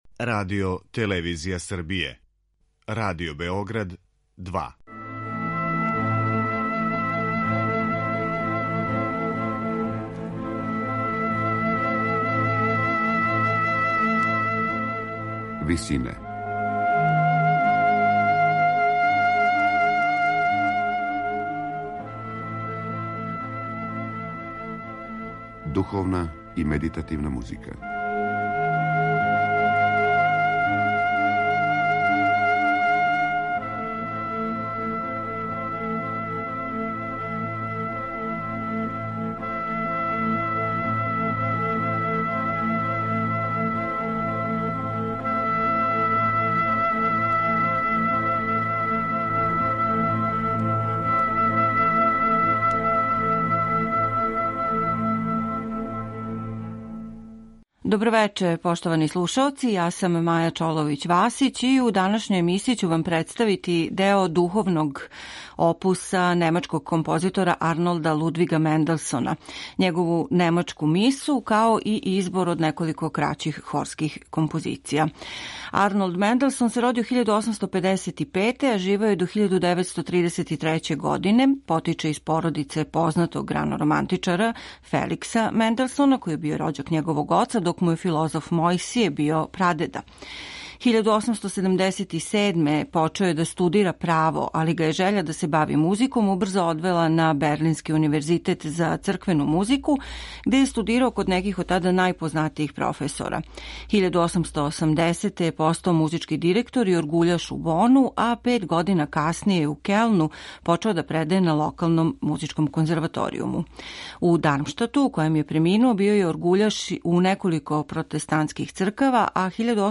У њој Менделсон у потпуности оживљава старе полифоне технике вешто их комбинујући са савременим хармонским језиком.